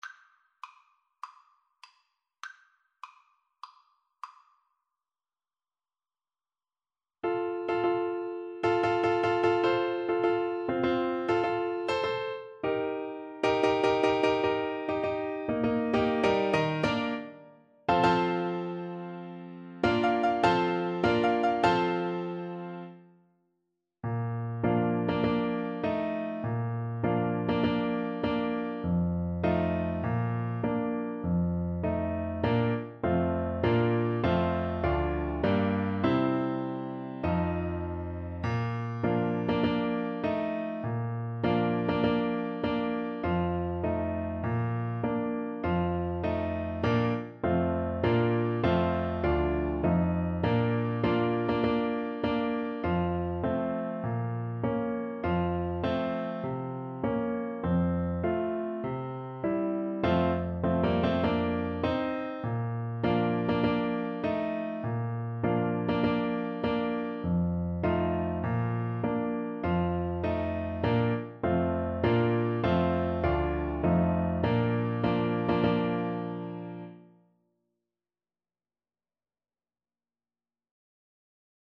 Play (or use space bar on your keyboard) Pause Music Playalong - Piano Accompaniment Playalong Band Accompaniment not yet available reset tempo print settings full screen
Bb major (Sounding Pitch) F major (French Horn in F) (View more Bb major Music for French Horn )
Maestoso = c. 100
Classical (View more Classical French Horn Music)